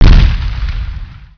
s_explode.wav